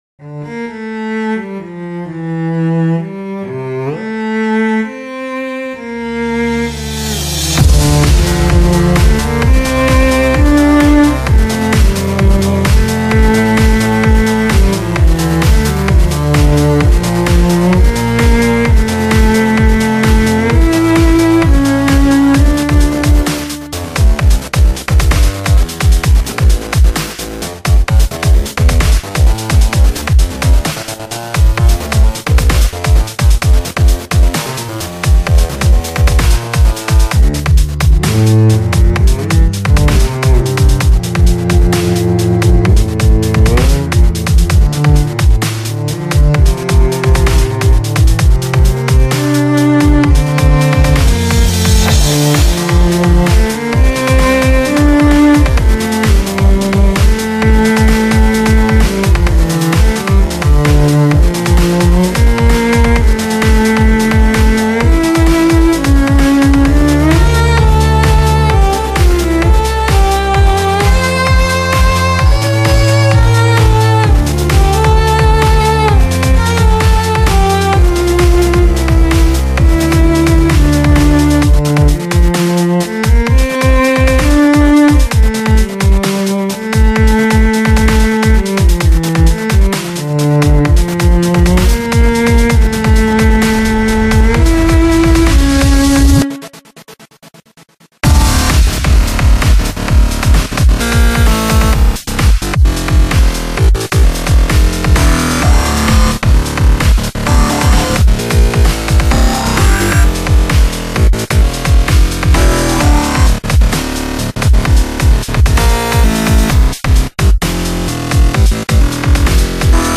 Cello and Contrabass